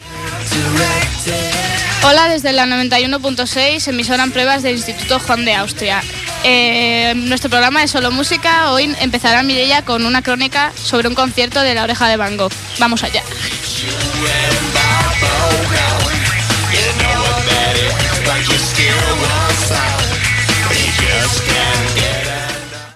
681f515a71e264ed3a9b444791af4374b8dfff15.mp3 Títol Ràdio Sant Martí Emissora Ràdio Sant Martí Titularitat Tercer sector Tercer sector Escolar Descripció Identificació de l'emissora en proves i música.